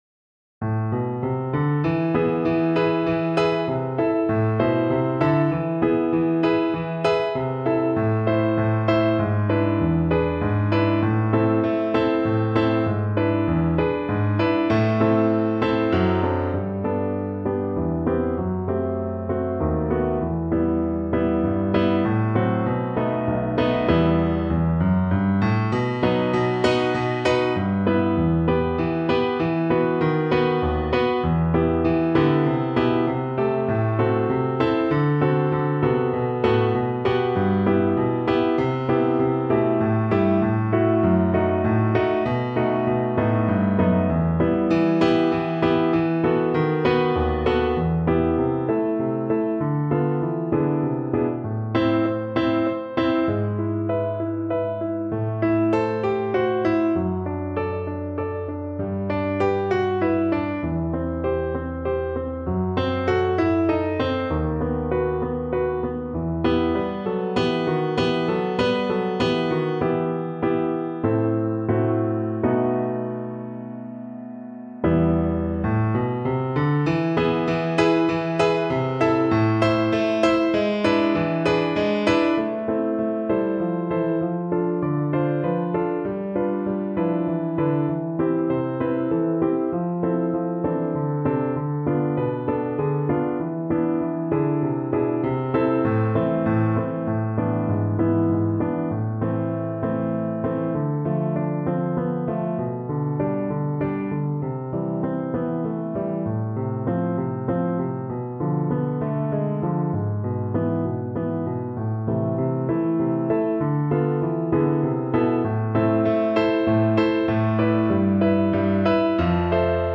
Classical Durante, Francesco Danza, Danza Violin version
Violin
A minor (Sounding Pitch) (View more A minor Music for Violin )
Allegro con spirito =138 (View more music marked Allegro)
3/4 (View more 3/4 Music)
Classical (View more Classical Violin Music)